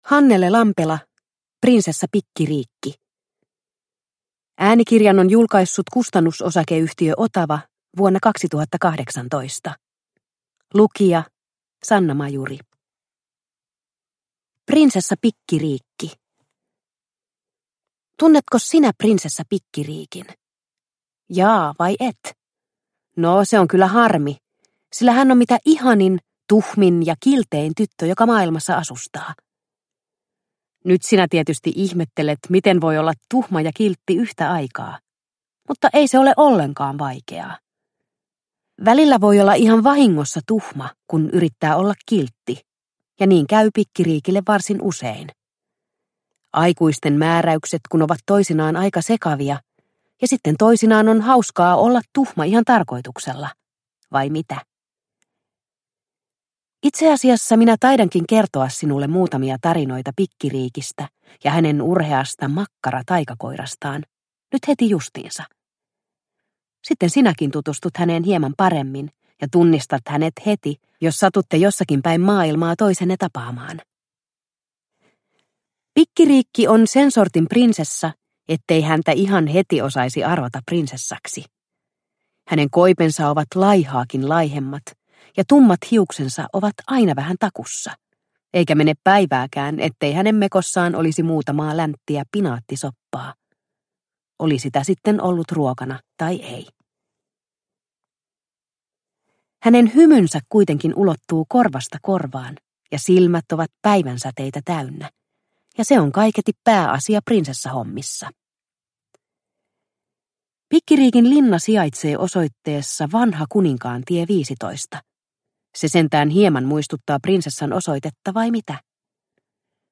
Prinsessa Pikkiriikki – Ljudbok – Laddas ner